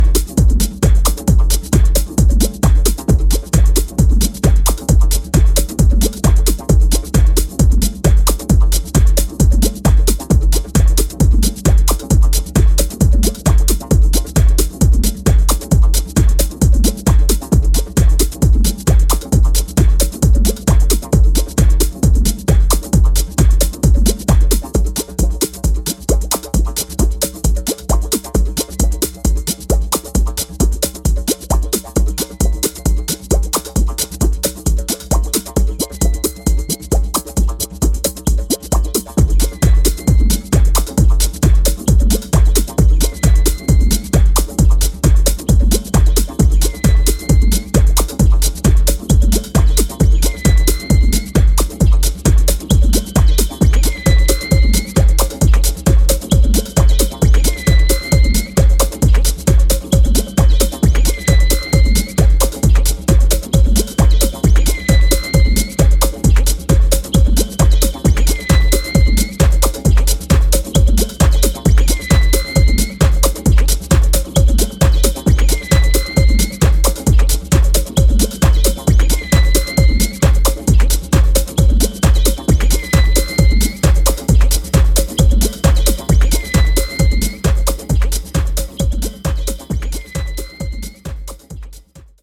Vinyl only minimal club cuts!